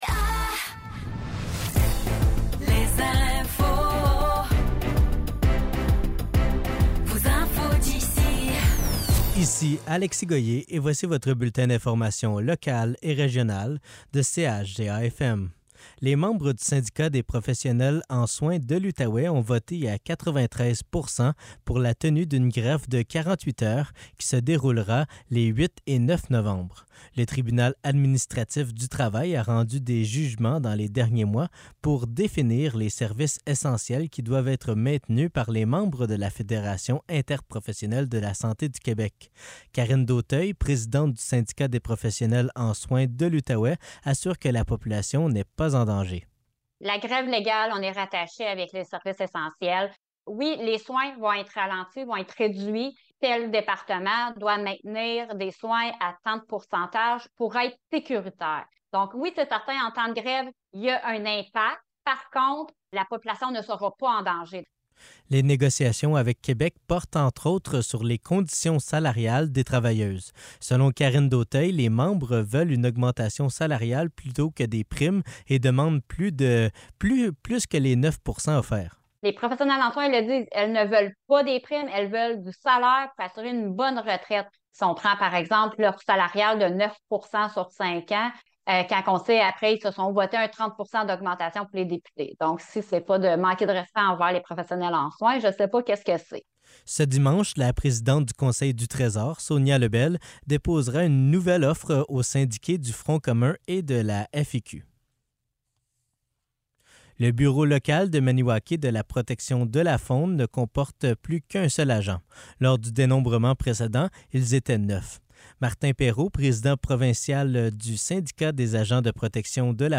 Nouvelles locales - 26 octobre 2023 - 15 h